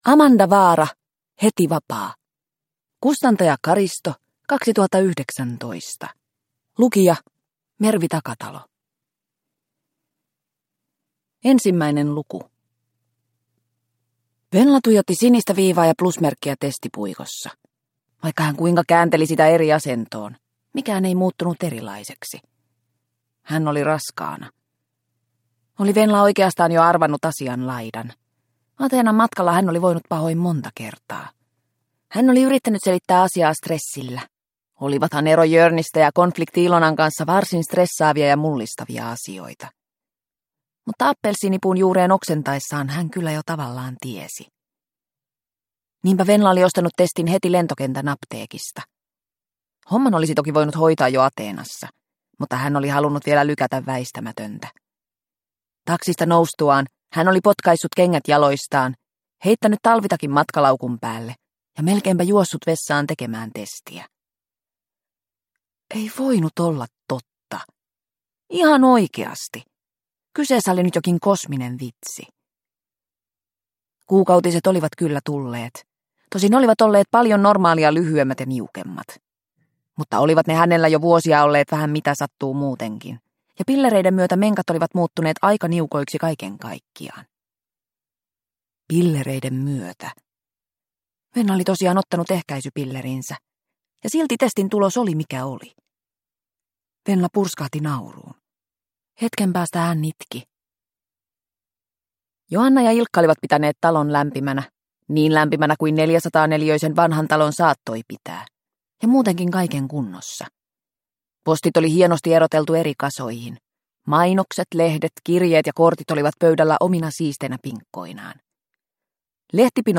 Heti vapaa – Ljudbok – Laddas ner